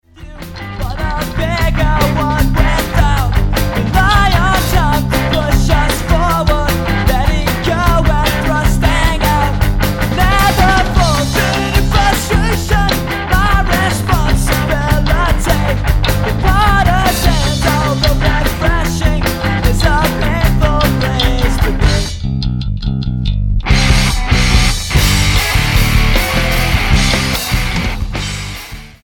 STYLE: Rock
abrasive, post punk, emo rock